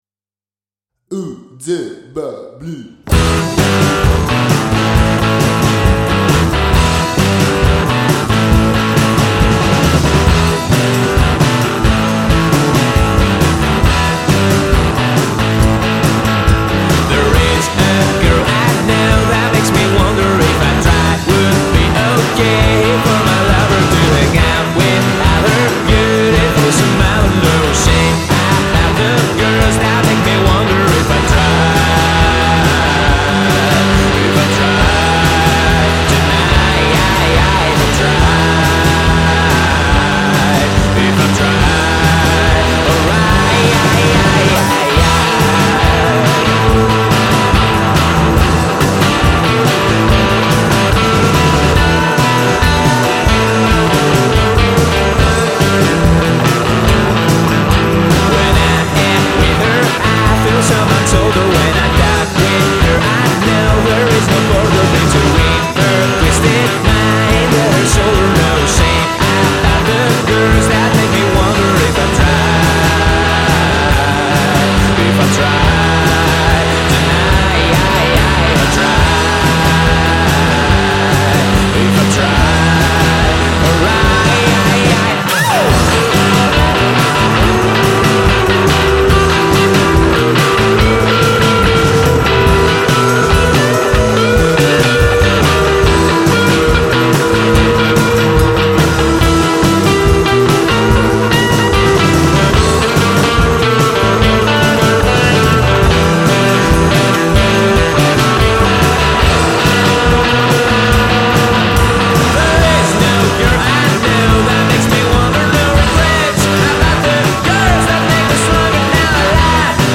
in 2016 and although being a garage rock band at its core
such as surf and noise rock.